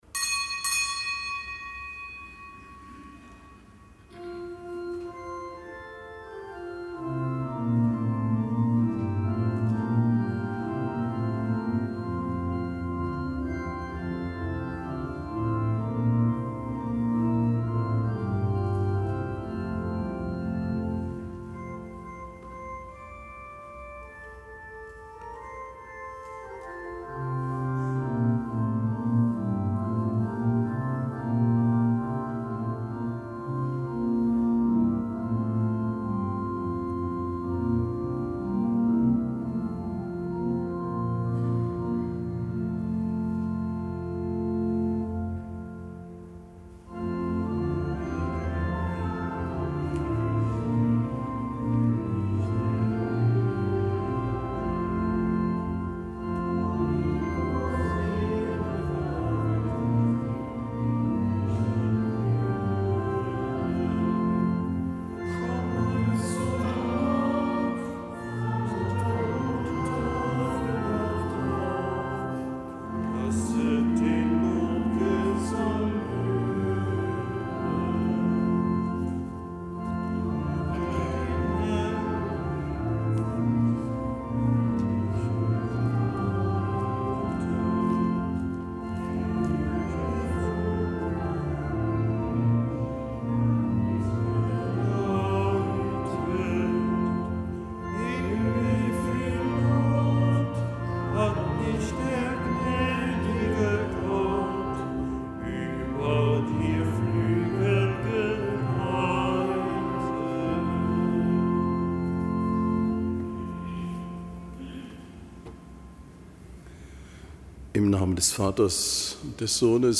Kapitelsmesse aus dem Kölner Dom am Donnerstag der fünften Woche im Jahreskreis.